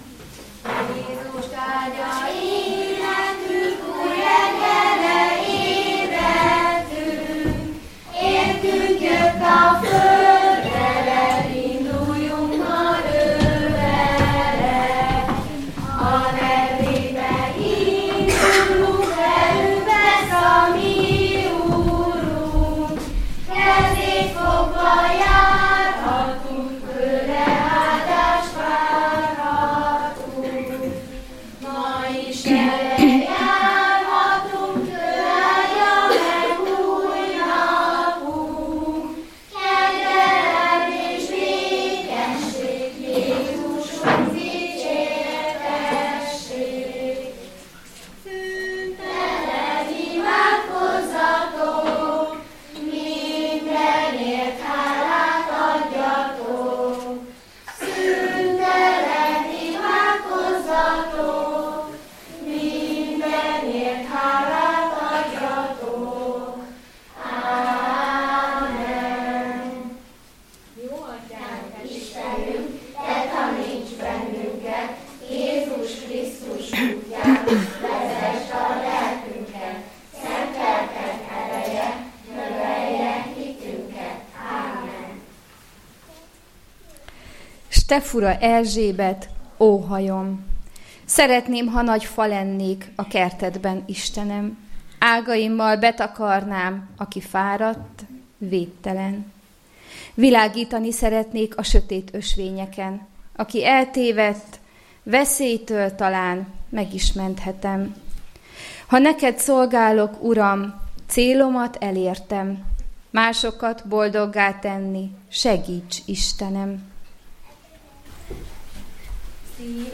Családi istentisztelet a Szivárvány csoport szolgálataival.